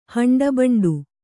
♪ haṇḍa baṇḍu